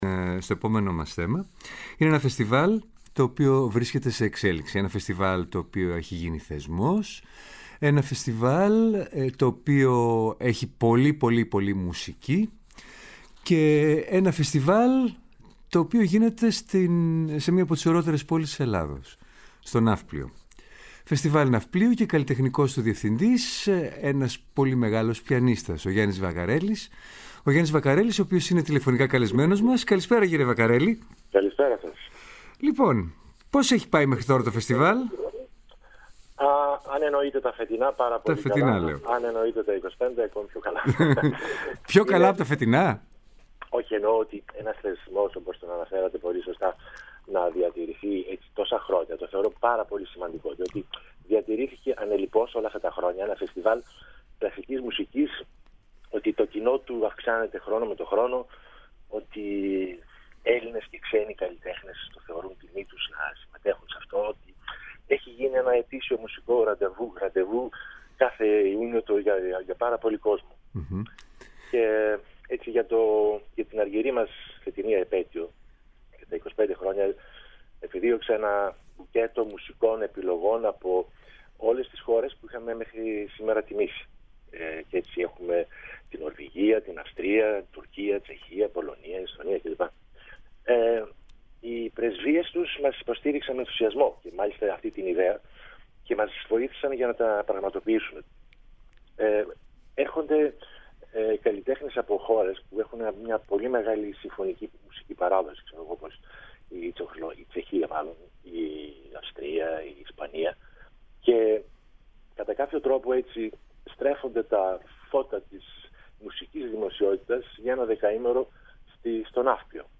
Συνέντευξη του πιανίστα